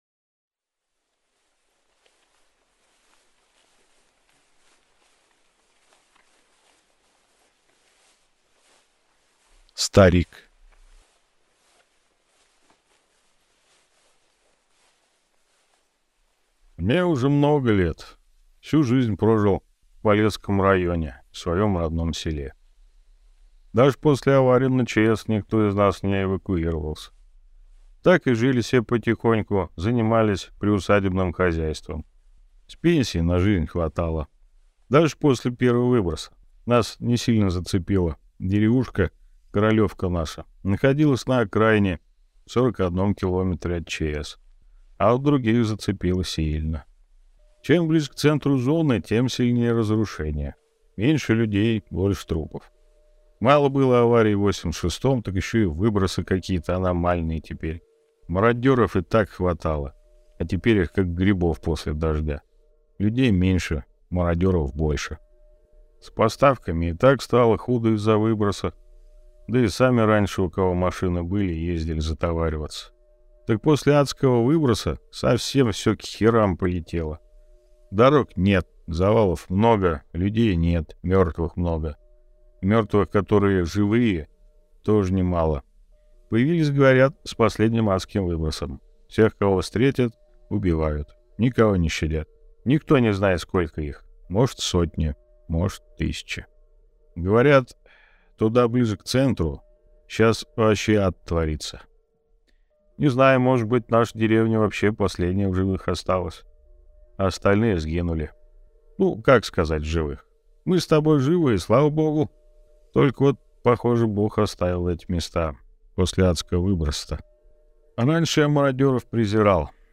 Аудиокнига Рассказы сталкеров. Сборник | Библиотека аудиокниг